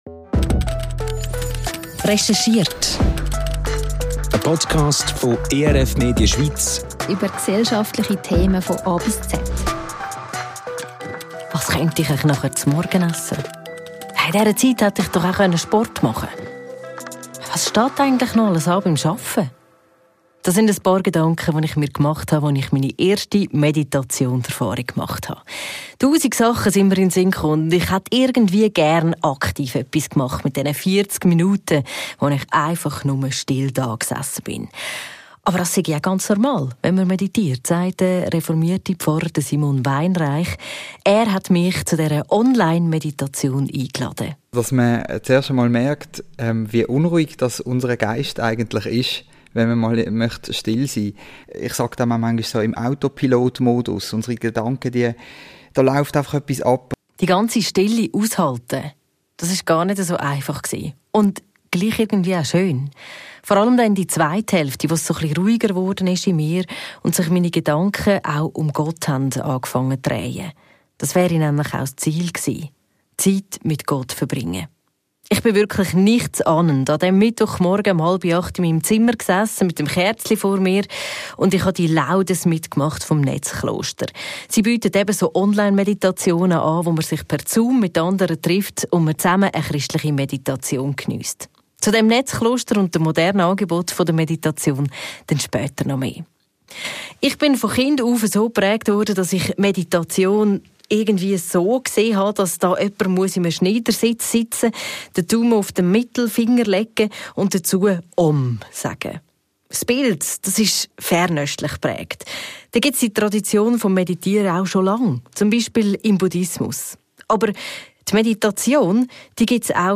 Es macht ruhiger und überlegter, sagt eine Meditations-Praktikerin im Podcast – und das, obwohl auch sie als Tatfrau anfangs ihre Zweifel am Meditieren hatte. Zusammen mit ihr erfahren wir in dieser Folge mehr über die christliche Meditation.
Und auch ihr könnt gleich selbst eine kleine Meditation ausprobieren.